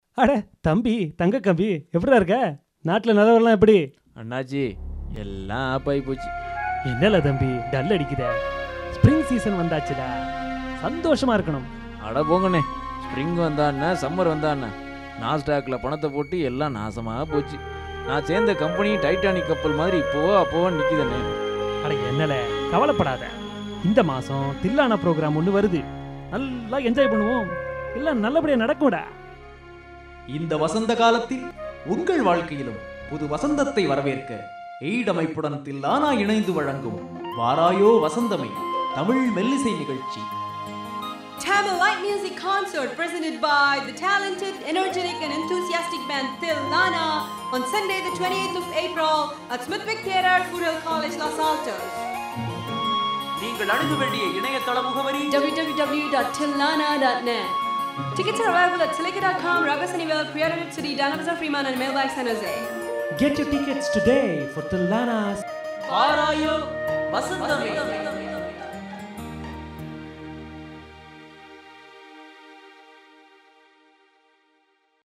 Radio Commercial